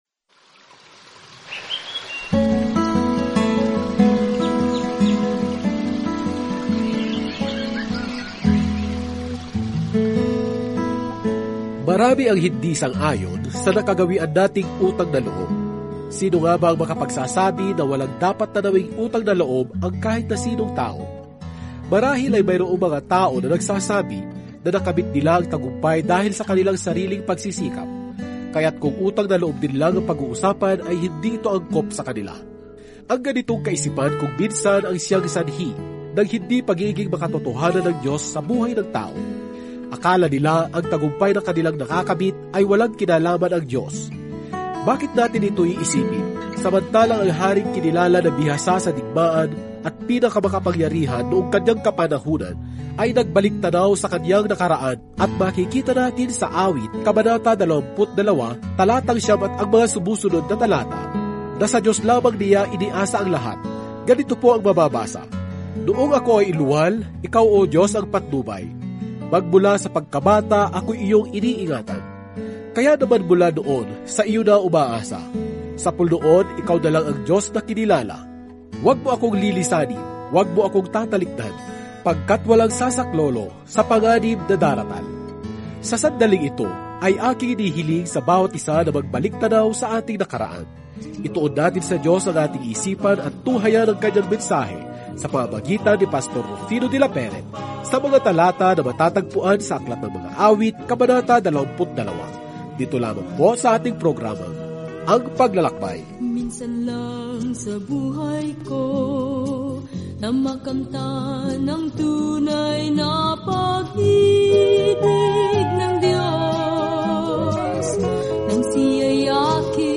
Araw-araw na paglalakbay sa Mga Awit habang nakikinig ka sa audio study at nagbabasa ng mga piling talata mula sa salita ng Diyos.